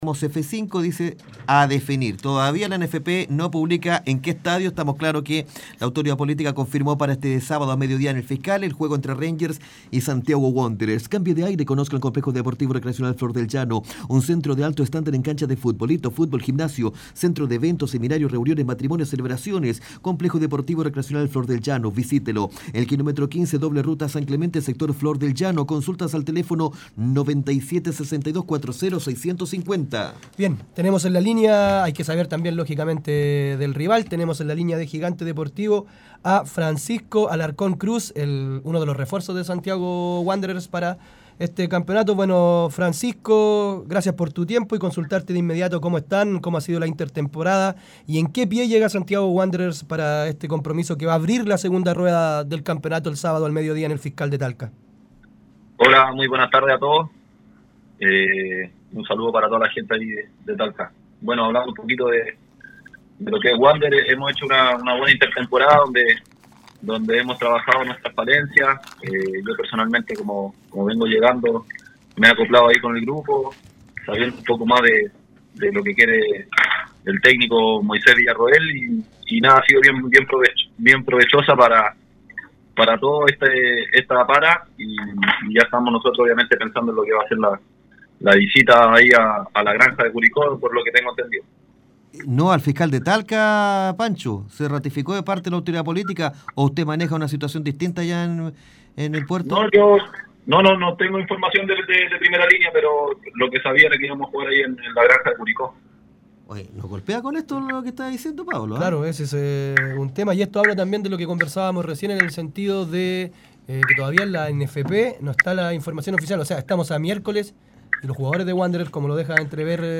Además de criticar la forma en que opera el mercado y el accionar de los dirigentes, el zaguero reveló en conversación con Gigante Deportivo , que pensaba que el compromiso era en Curicó.